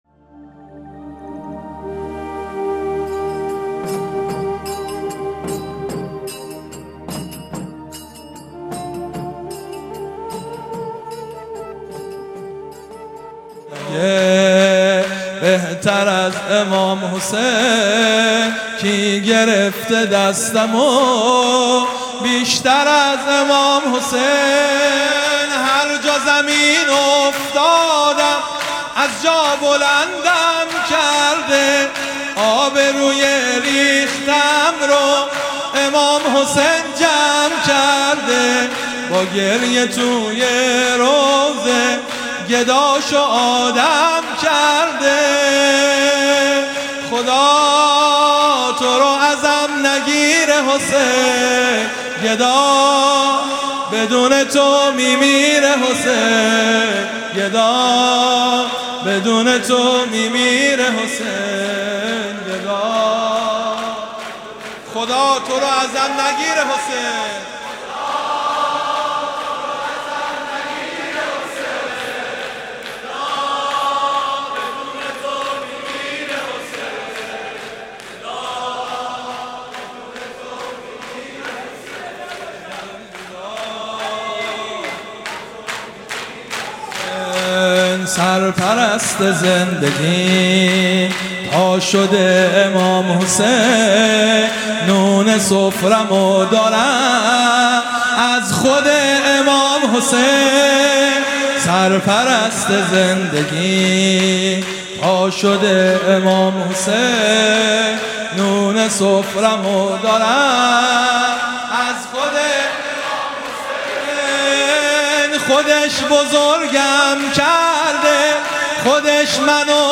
شور | هر جا زمین افتادم...
مداحی شو
شب سوم محرم 1401